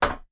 Other Sound Effects
alt-toasterstep3.wav